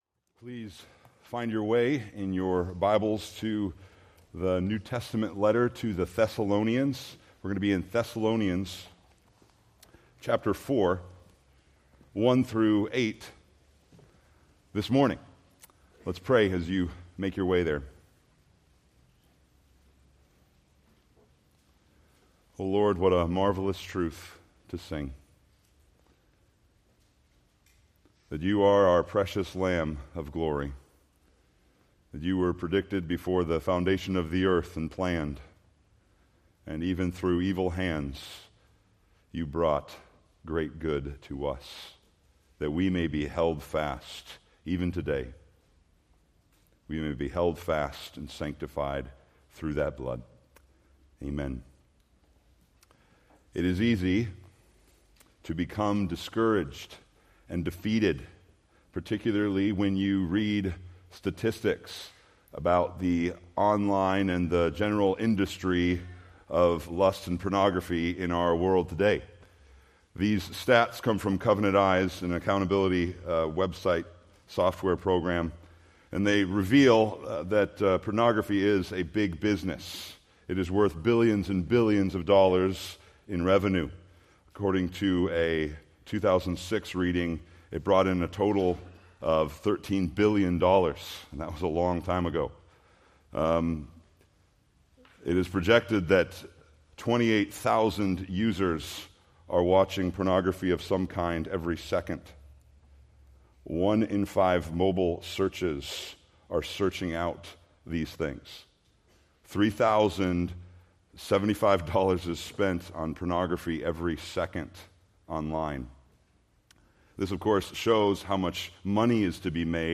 Preached October 27, 2024 from 1 Thessalonians 4:1-8